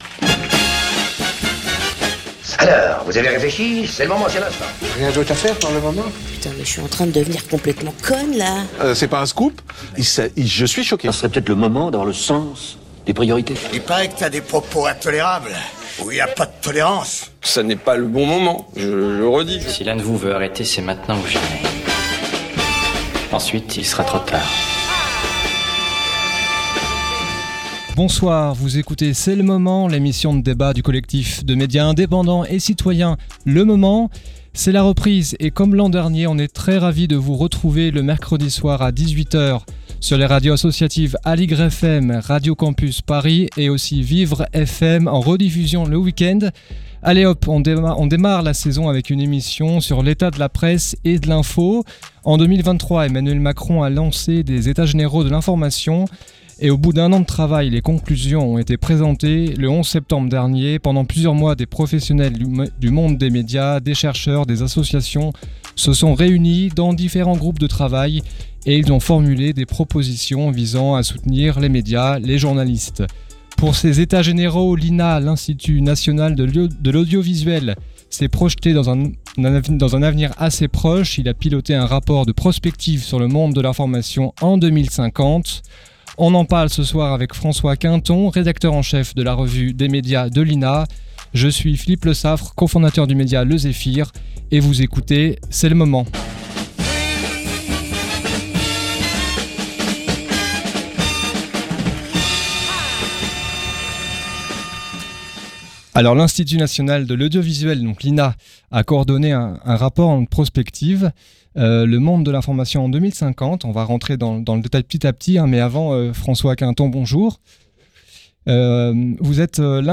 Retrouvez-nous chaque mercredi à 18h sur les radios associatives Aligre FM, Radio Campus Paris, et en rediffusion le week-end sur Vivre FM.